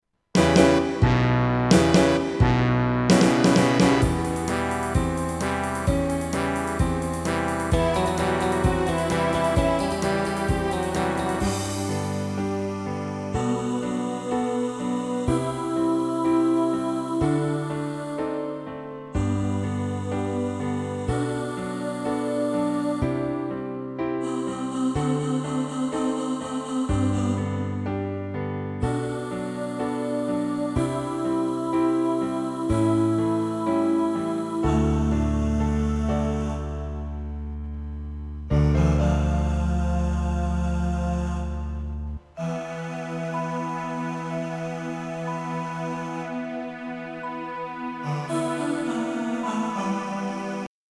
Live And Let Die (Baritone) | Ipswich Hospital Community Choir